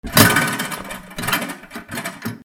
体重計 ばね式
『ガターン』